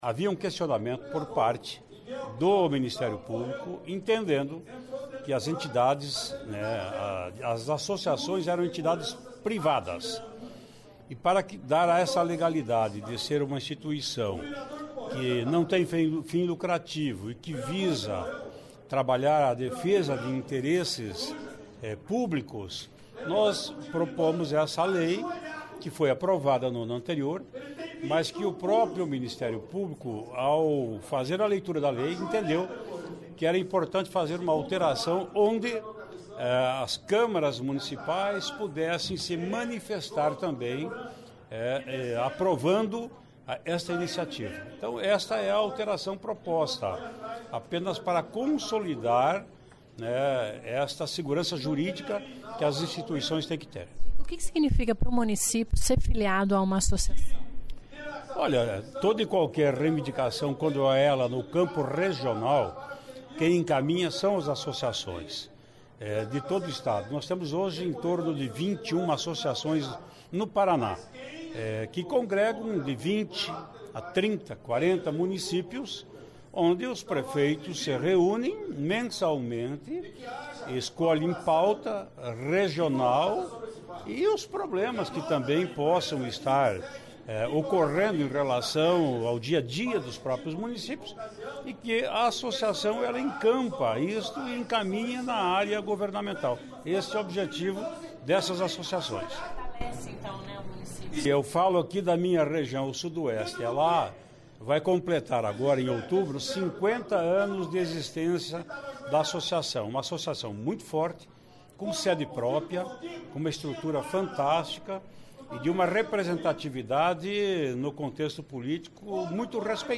Ouça entrevista com o rpesidente da Assembleia explicando as  alterações no   projeto que regulamenta associações de municípios e foratlece as entidades e os municípios filiados.